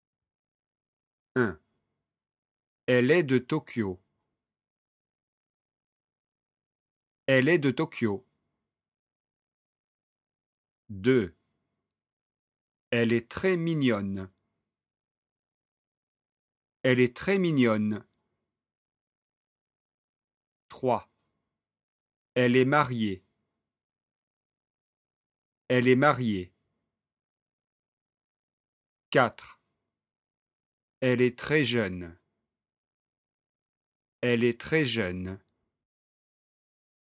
🔷 PRONONCER :